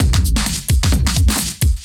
OTG_DuoSwingMixD_130a.wav